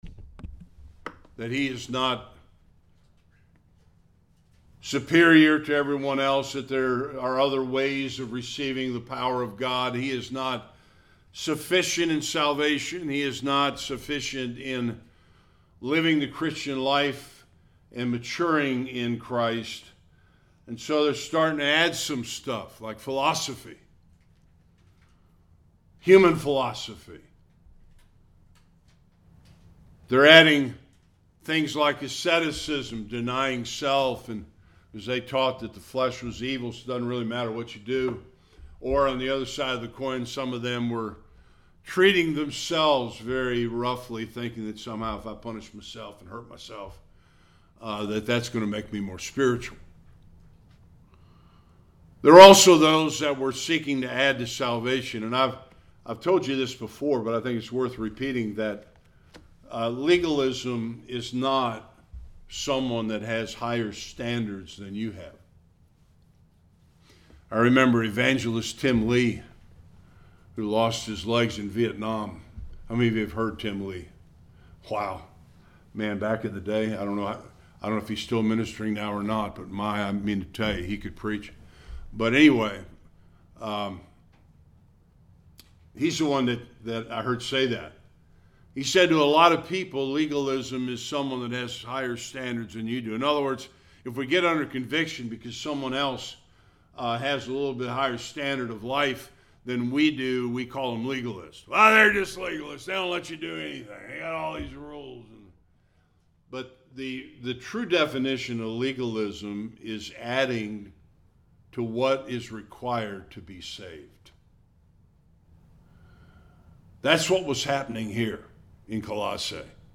16-17 Service Type: Sunday Worship Paul explains the error and destruction of legalism in the church.